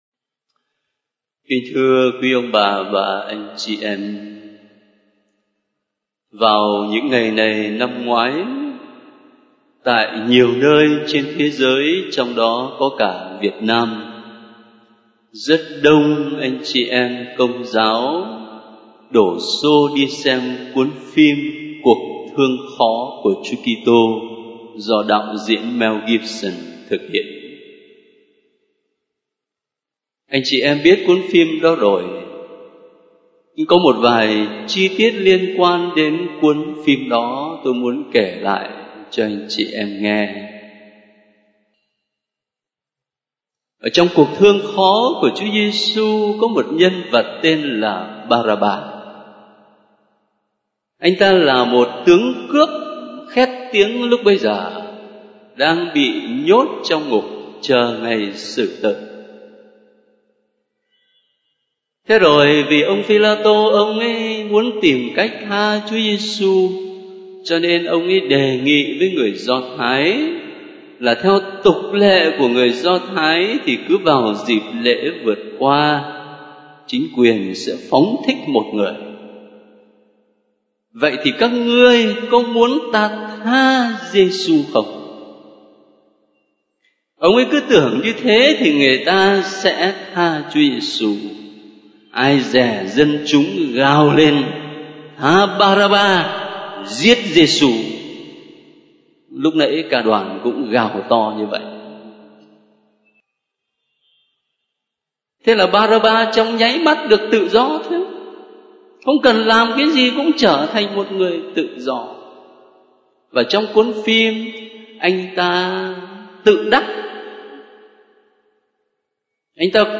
* Ca sĩ: Gm. Phêrô Nguyễn Văn Khảm
* Thể loại: Nghe giảng